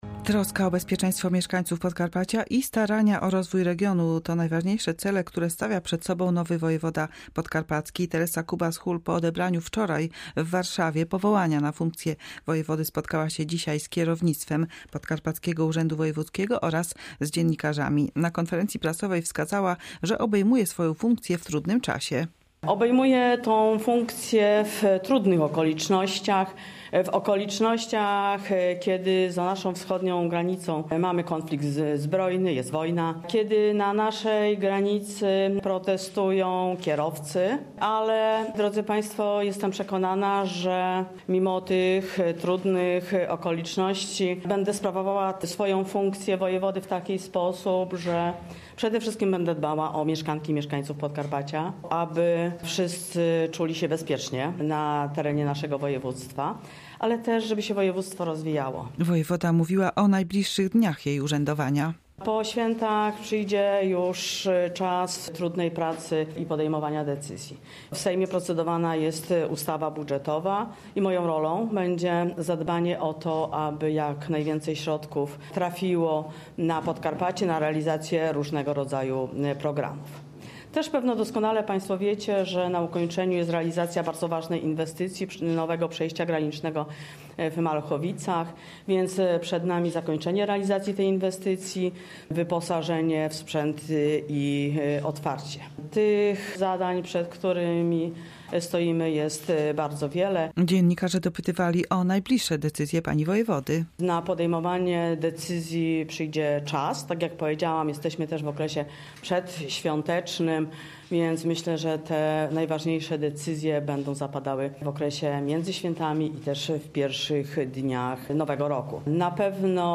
Na konferencji prasowej wskazała, że obejmuje swoją funkcję w trudnym czasie konfliktu zbrojnego za wschodnią granicą oraz protestu polskich przewoźników na kilku przejściach granicznych.